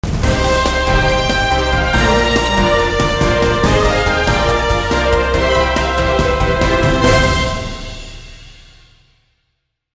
دانلود آهنگ تیزر خبری از افکت صوتی اشیاء
جلوه های صوتی